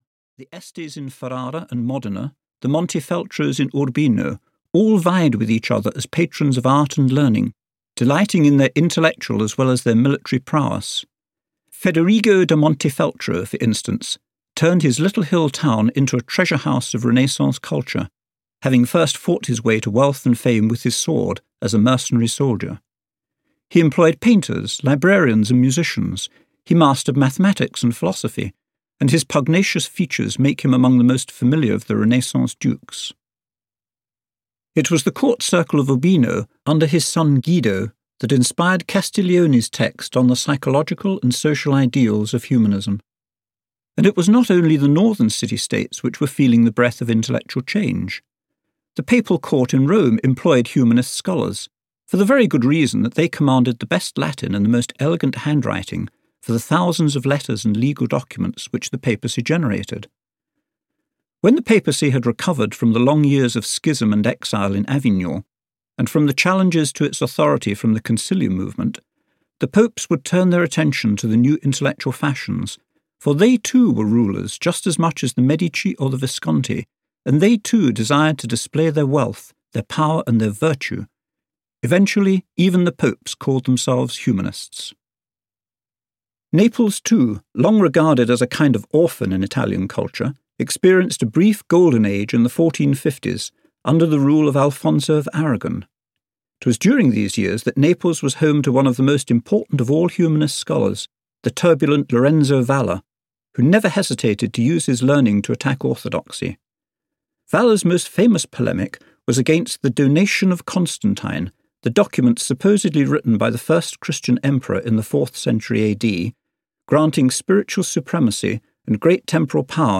Audio knihaThe Renaissance – In a Nutshell (EN)
Ukázka z knihy